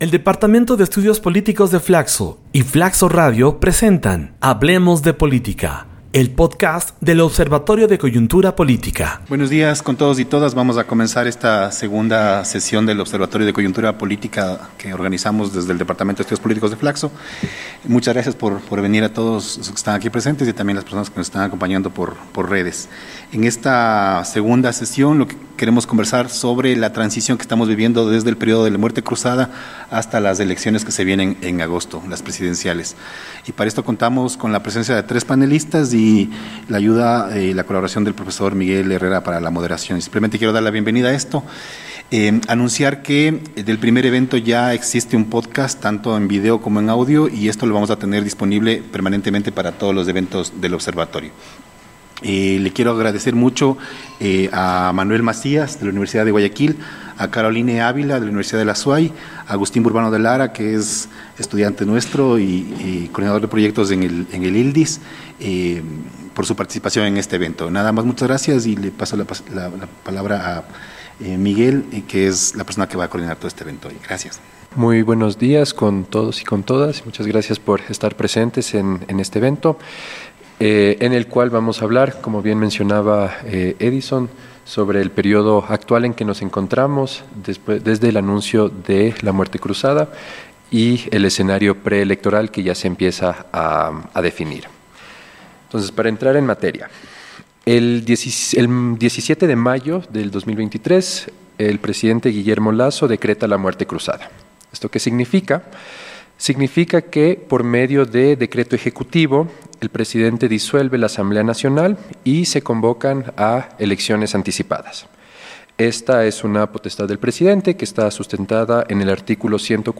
Para analizar este panorama político en Ecuador participaron de la segunda edición de Hablemos de Política los académicos